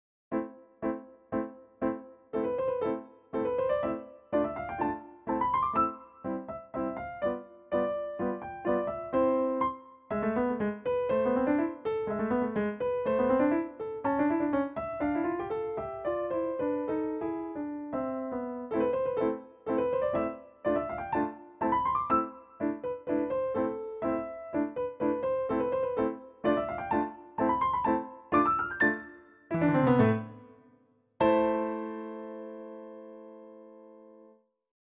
１６分音符の練習曲的運びが、右から始まり中間部では左手に。。。
なんといっても安定のハ長調。
さらに、スタッカート、和音、スラー、連符。練習曲的要素が愛らしくもふんだんに散りばめられています。